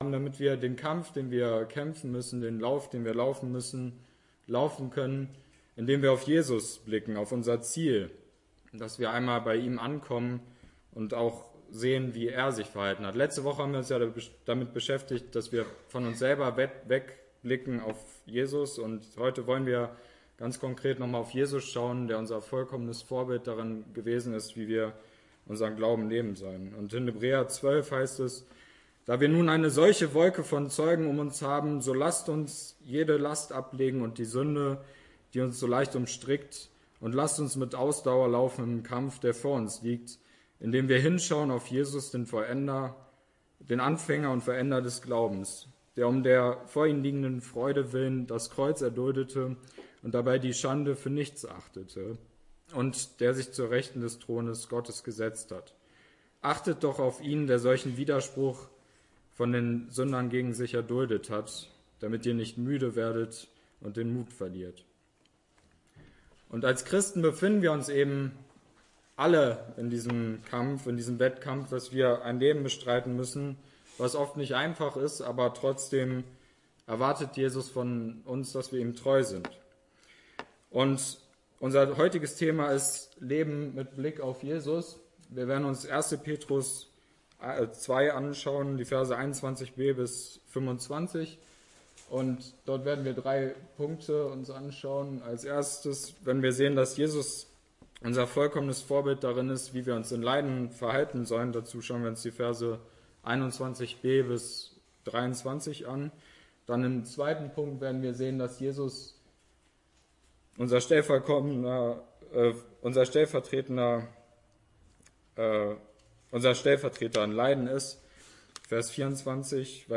Evangelische Gemeinde Gevelsberg e.V. - Predigten